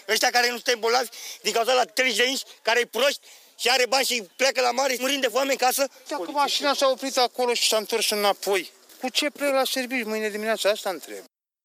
22iul-21-Vox-Cartojani.mp3